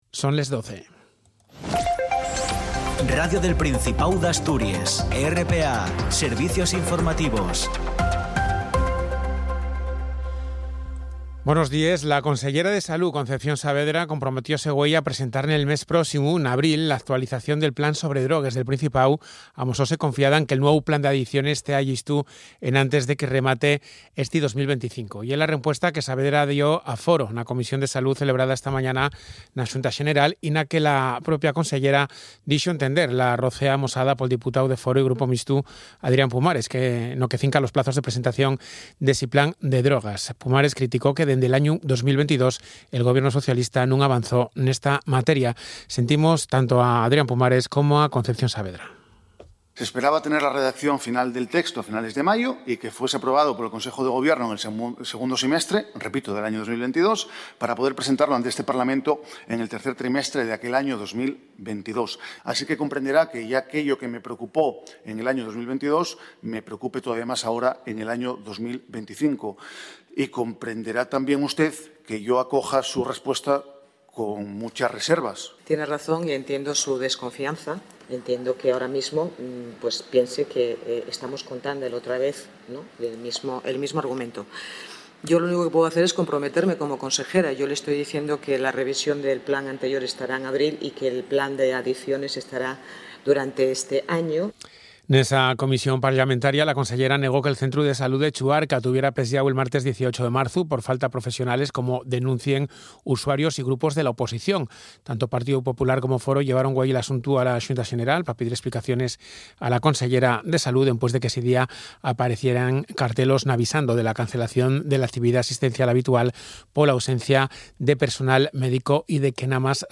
El boletín de las 12:00 horas tiene una duración de 10 minutos y se emite en asturiano. La actualidad general del día en nuestra lengua.